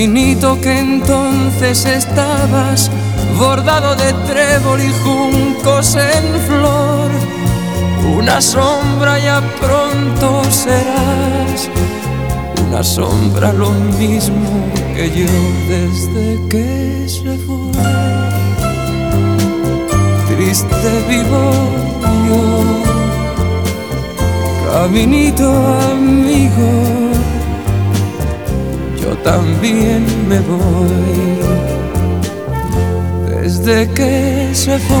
# Baladas and Boleros